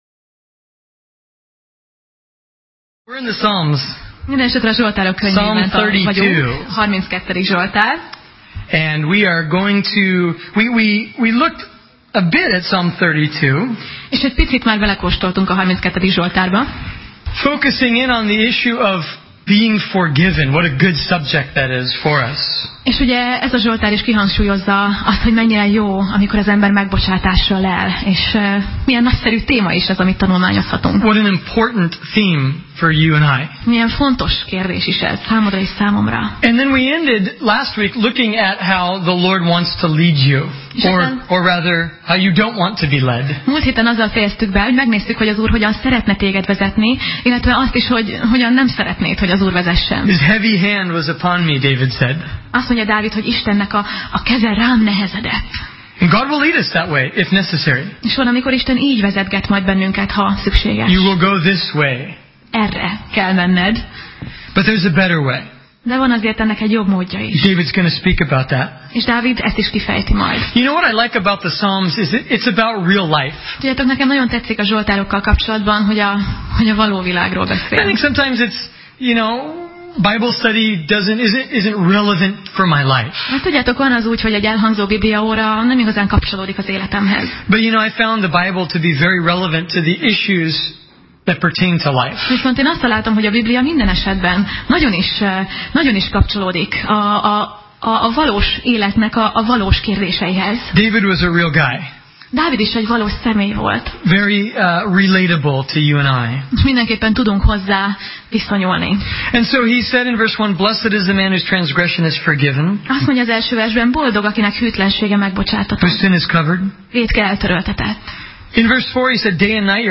Zsoltárok Passage: Zsoltárok (Psalm) 32:5–34:18 Alkalom: Szerda Este